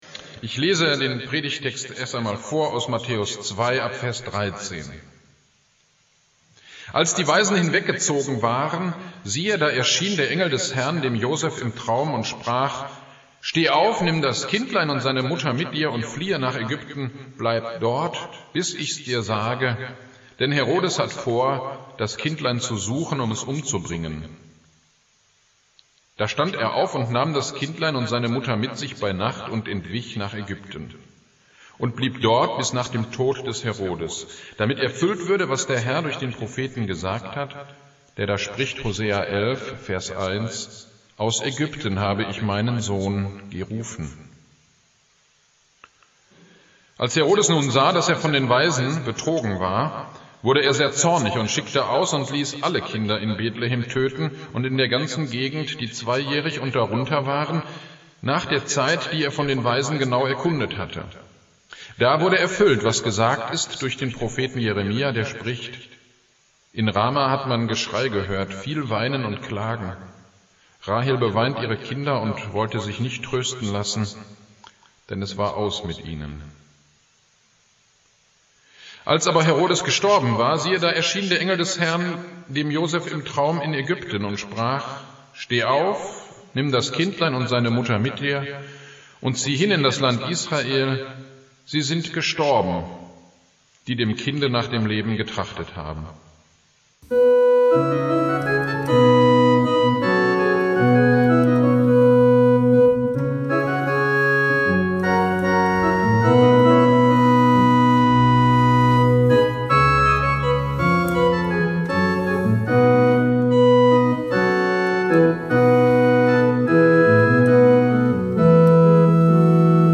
Predigt am 10.01.2021 zu Matthäus 2,13-20